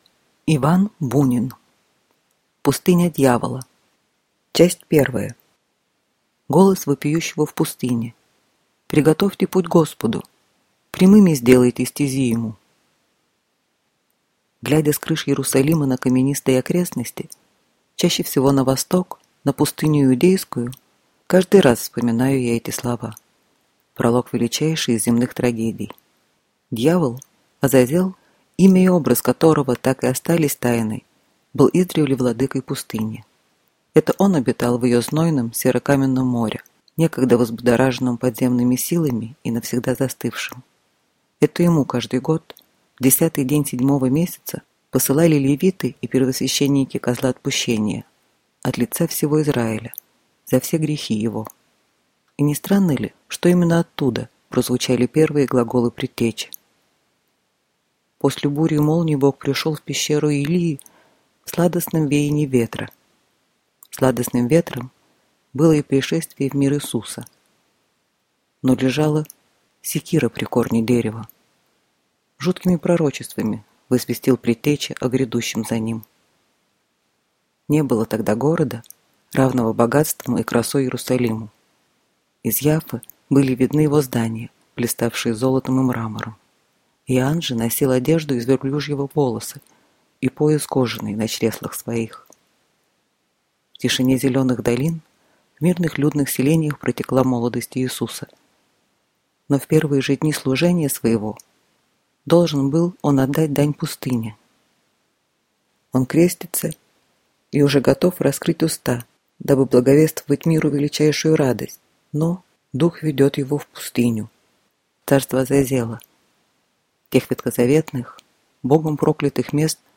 Аудиокнига Пустыня дьявола | Библиотека аудиокниг